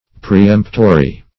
preemptory - definition of preemptory - synonyms, pronunciation, spelling from Free Dictionary
Preemptory \Pre*["e]mpt"o*ry\